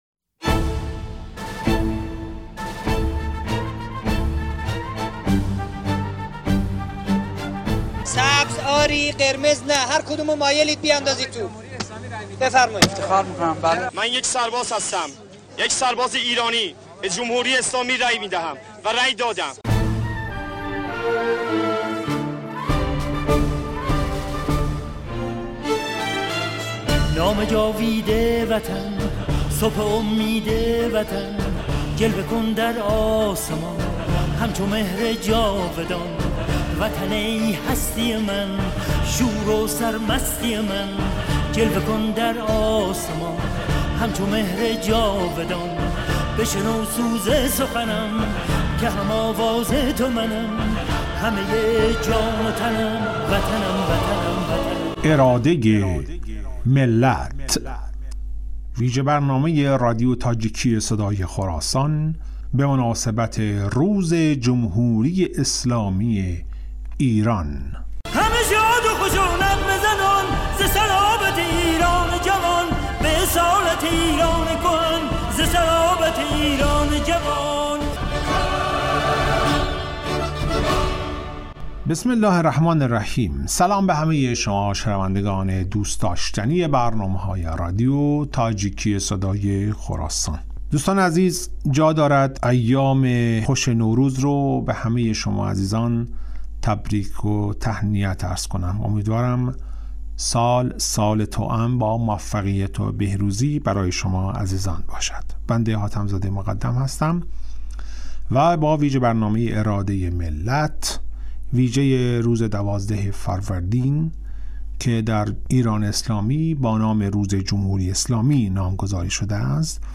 "اراده ملت" نام ویژه برنامه ای است که به مناسبت روز 12 فروردین (1 آوریل) ؛ روز جمهوری اسلامی در رادیو صدای خراسان تهیه و پخش شده است.